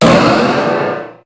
Cri de Méga-Latias dans Pokémon HOME.
Cri_0380_Méga_HOME.ogg